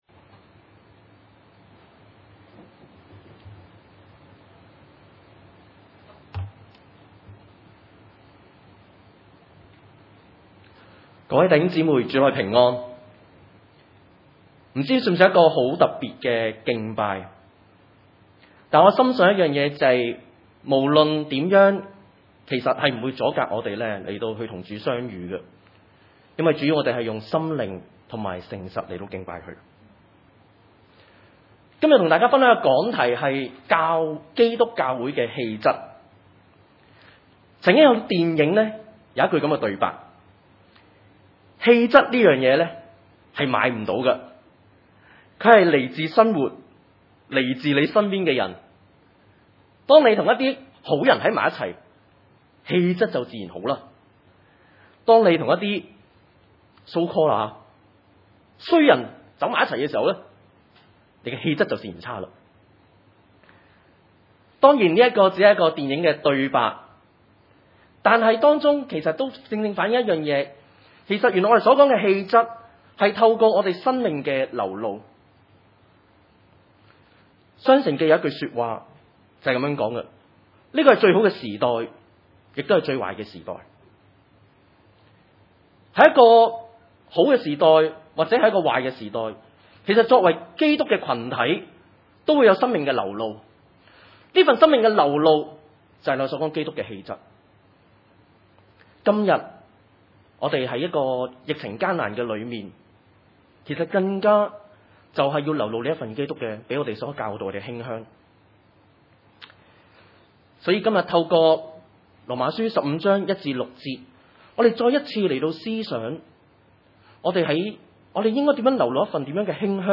羅十五1-6 崇拜類別: 主日午堂崇拜 經文：羅馬書 第十五章1-6節（聖經‧新約） 1.